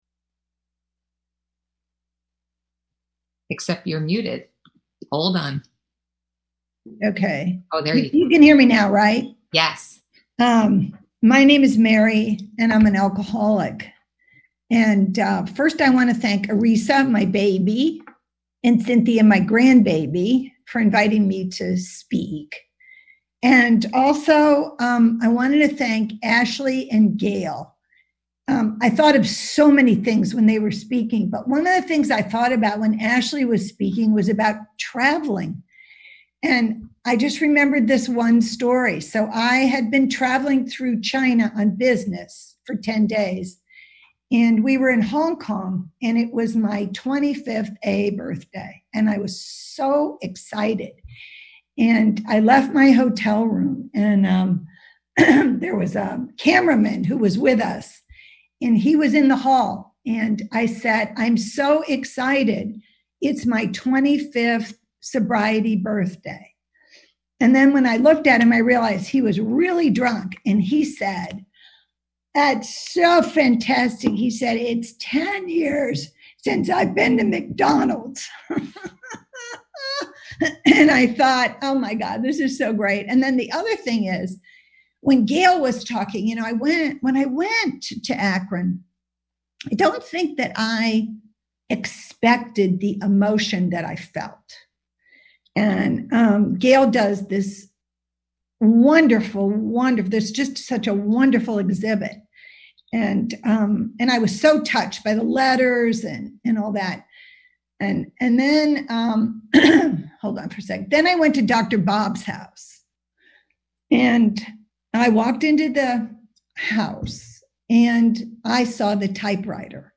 Women's Wisdom AA Weekend